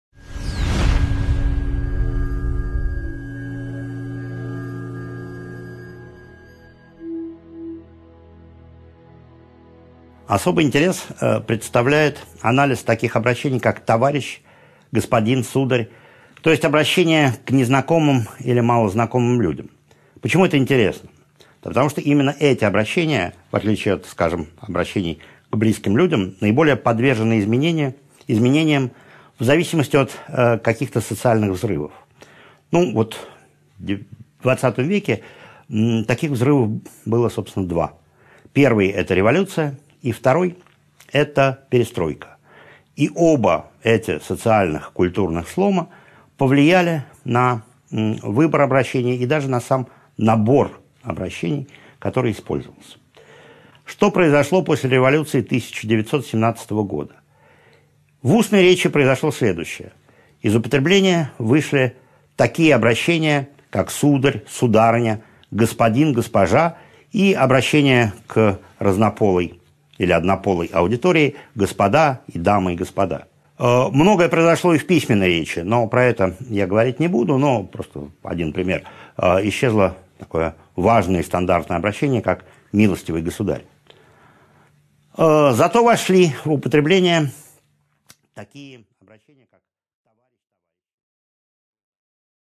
Аудиокнига 5.3 «Товарищ» и другие обращения к незнакомым и малознакомым людям | Библиотека аудиокниг